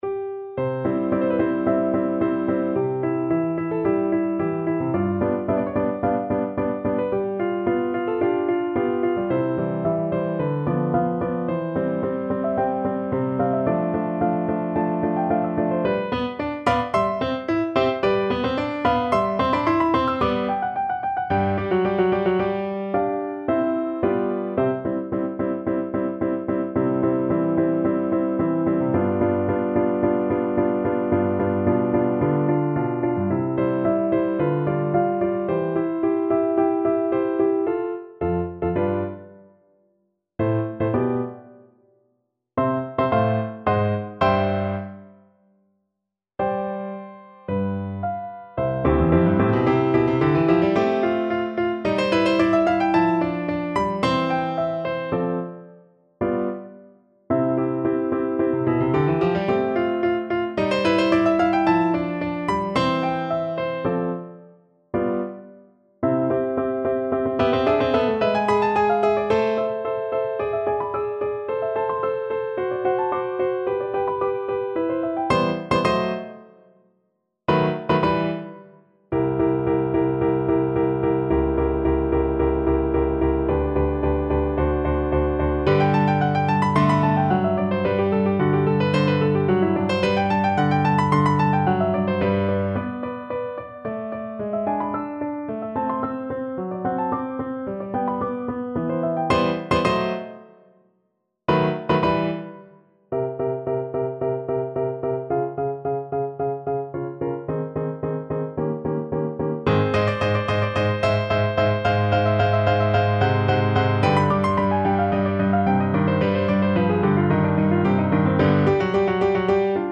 Play (or use space bar on your keyboard) Pause Music Playalong - Piano Accompaniment Playalong Band Accompaniment not yet available reset tempo print settings full screen
Andante =110
C major (Sounding Pitch) (View more C major Music for Tenor Voice )
Classical (View more Classical Tenor Voice Music)